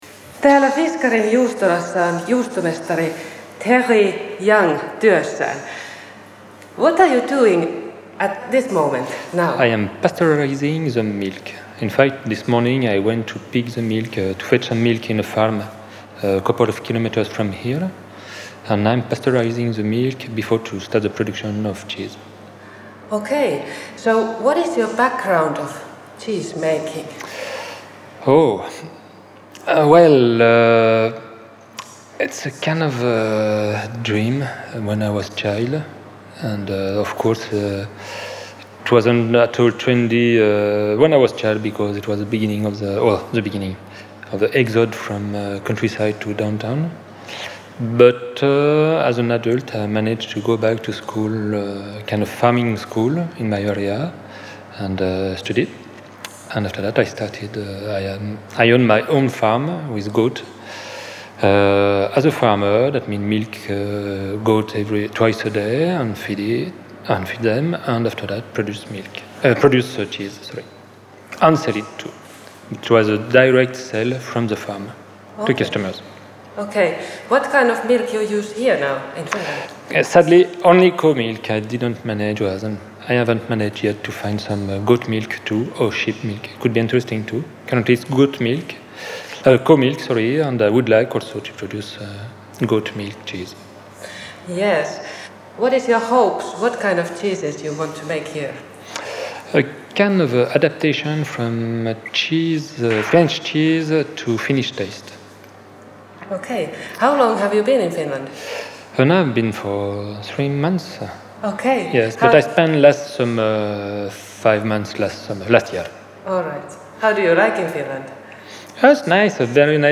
Nyt pöydän ääreen kokoontuu neljä intohimoista olutexperttiä ja kuullaan erilaisia panimotarinoita sekä maistellaan yhdessä paikallisia oluita.